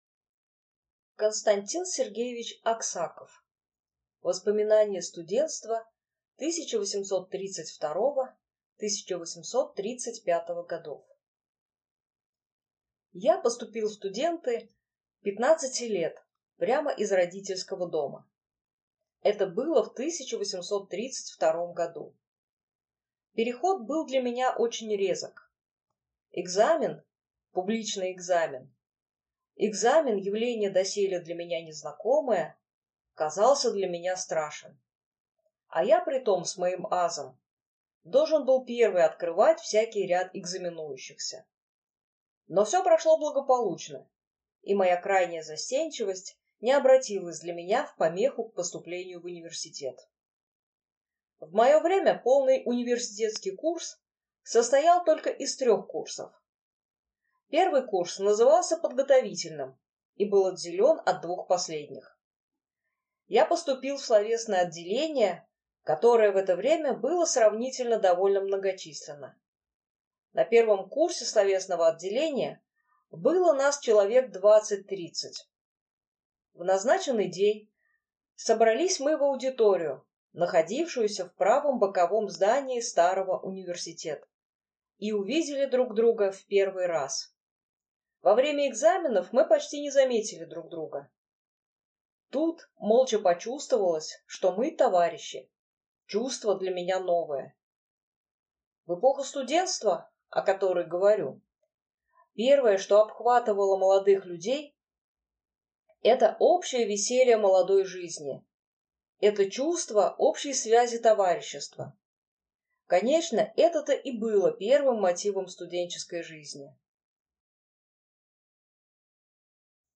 Аудиокнига Воспоминание студентства 1832–1835 годов | Библиотека аудиокниг